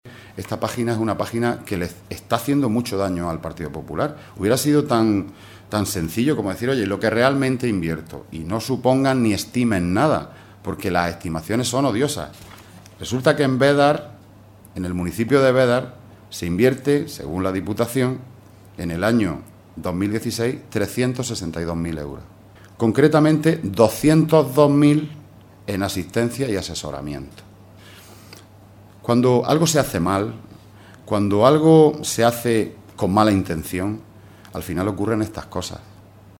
Rueda de prensa que ha ofrecido el portavoz del PSOE en la Diputación de Almería, Juan Antonio Lorenzo, acompañado por el diputado provincial Francisco García